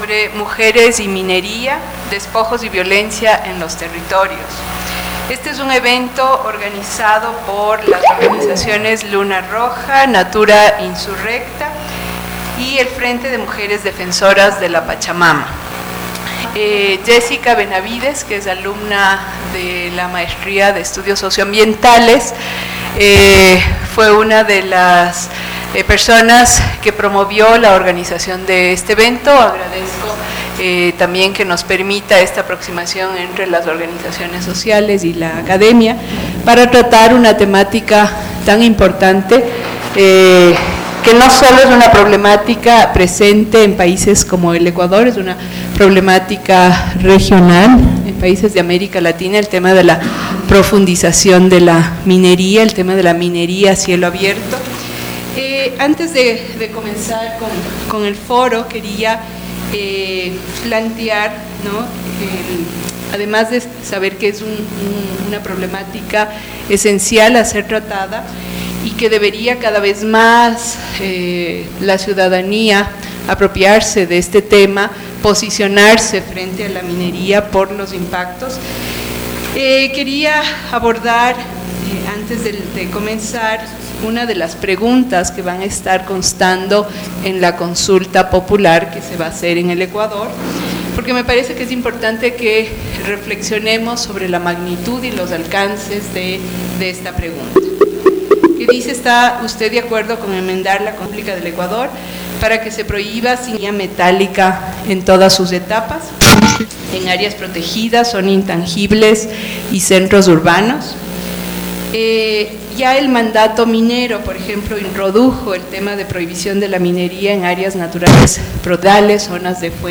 FLACSO Ecuador, su Departamento de Desarrollo, Ambiente y Territorio, conjuntamente con las organizaciones sociales del Frente de mujeres defensoras de la Pachamama, Luna Roja y Natura insurrecta, organizaron el Foro: Mujeres y minería: despojos y violencia en los territorios. Las temáticas aboradadas fueron: Los impactos de la mega minería en las mujeres (Luna Roja); Las perspectivas extractivistas en el Ecuador (Natura Insurrecta) y Procesos de resistencia de mujeres campesinas (Frente de mujeres defensoras de la Pachamama) Mostrar el registro completo del ítem Ficheros en el ítem Nombre